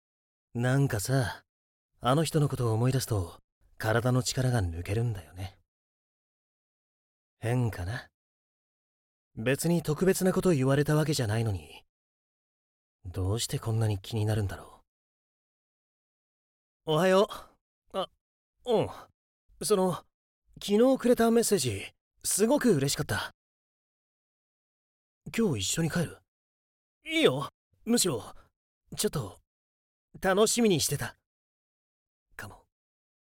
Voice Samples
１０代～
青年
かっこよさげ高校生.mp3